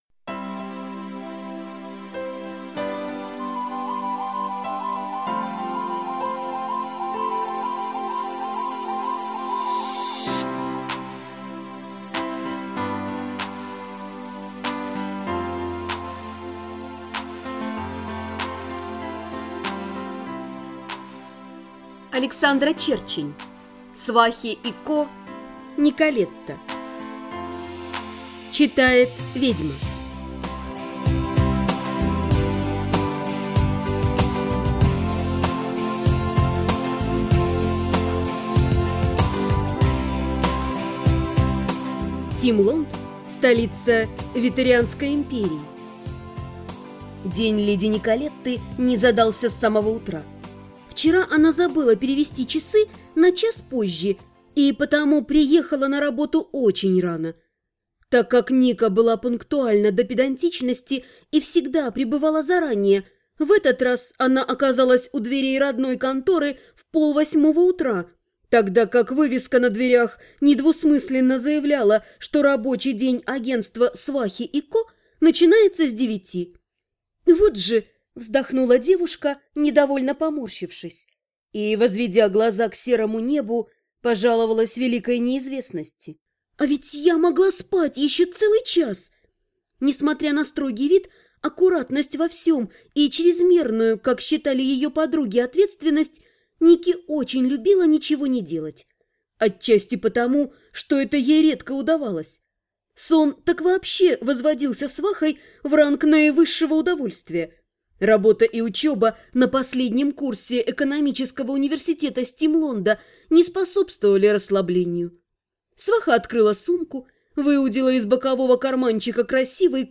Аудиорассказ Свахи и Ко. Николетта - Ведьма